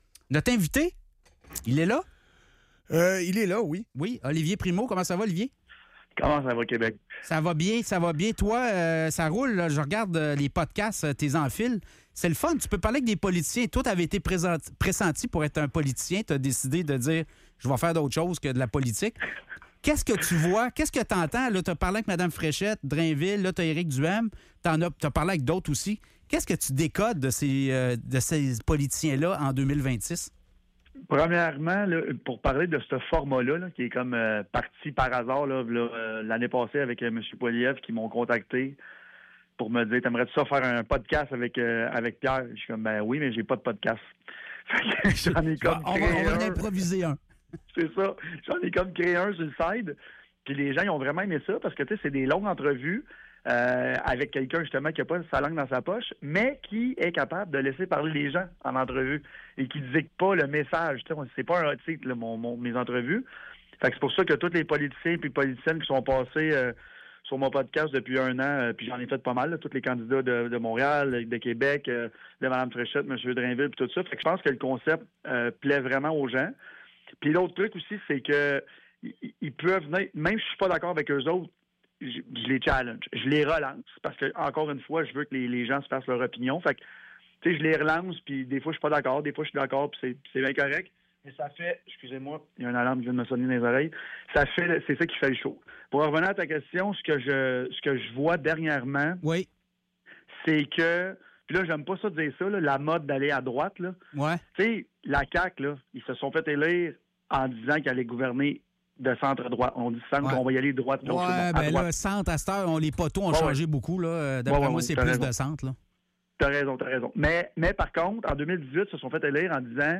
Entrevue avec Olivier Primeau